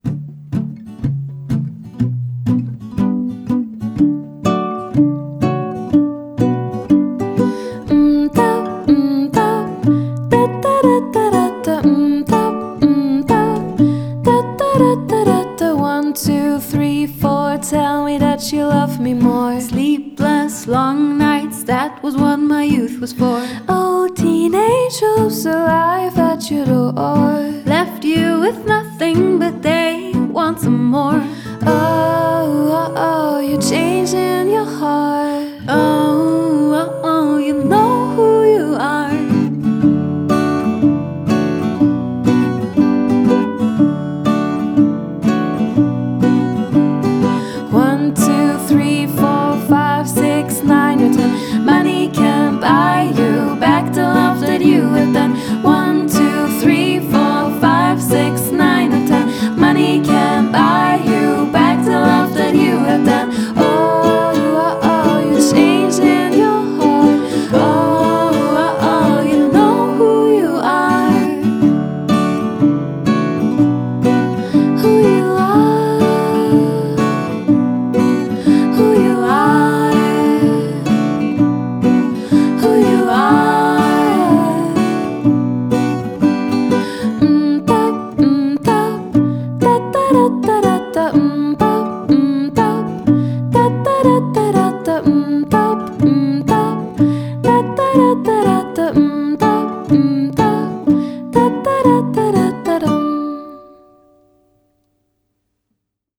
Trio
Acoustic-Trio für Trauung, Agape & eure Feierlichkeiten.
Indie & Folk